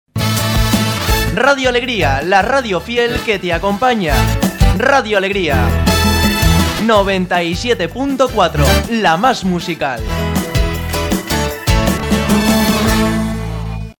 Identificació de l'emissora i freqüència